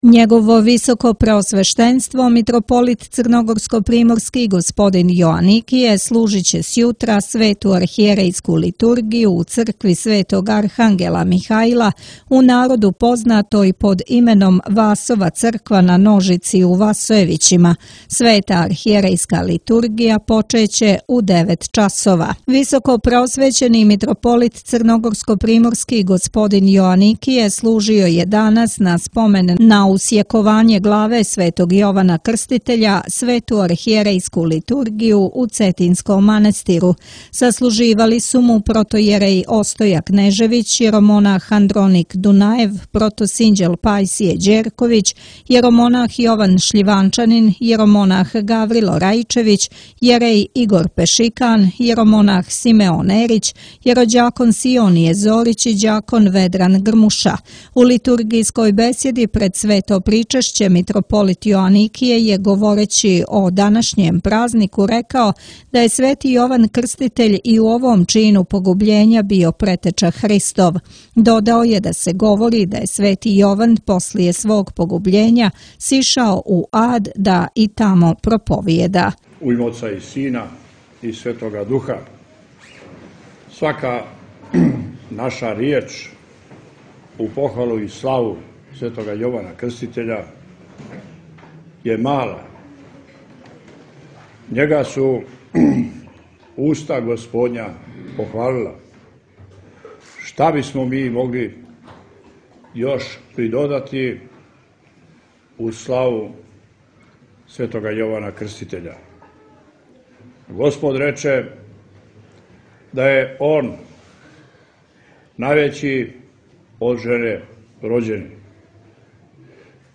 Митрополит Јоаникије на Литургији у Цетињском манастиру: Смрт праведника свијетли и опомиње • Радио ~ Светигора ~
У литургијској бесједи пред Свето причешће Митрополит Јоаникије је, говорећи о данашњем празнику рекао да је Свети Јован и у овом чину погубљења био Претеча Христов.